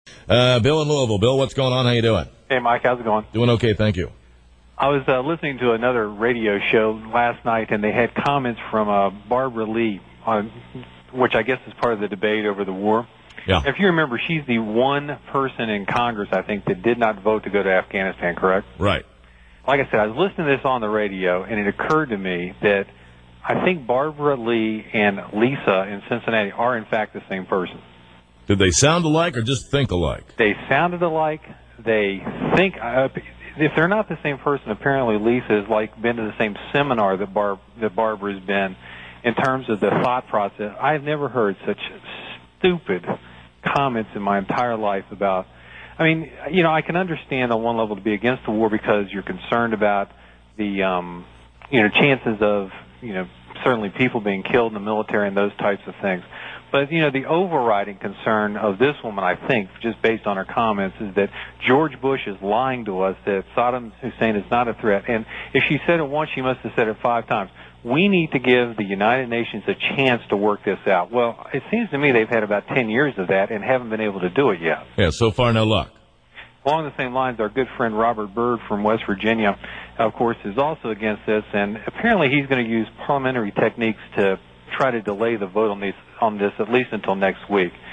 United States English
General American English is recognisable by certain features, such as a retroflex [ɺ], the tapping of intervocalic [t], as in water [wɒ:ſɚ], a long, slight nasalised realisation of the TRAP vowel and a possible lowering and unrounding of the vowel in the LOT lexical set.
UnitedStates_General.wav